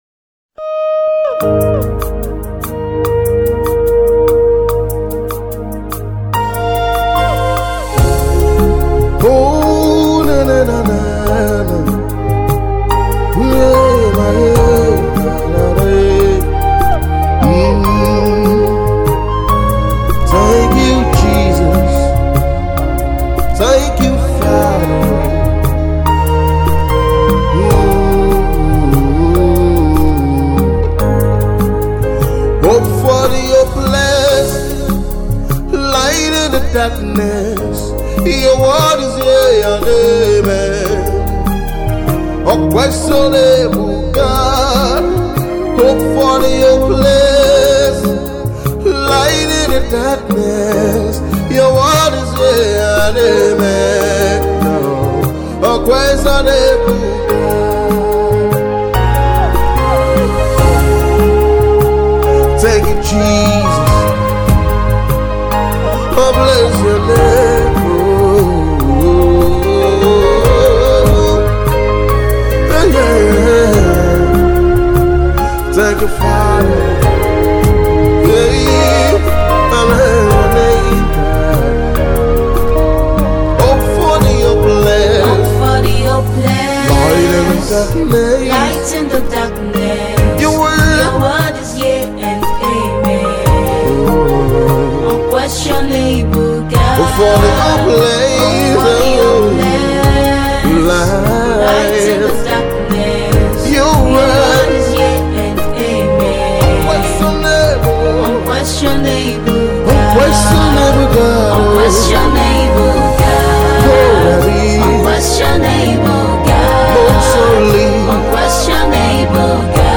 Afrocentric midtempo track
song of worship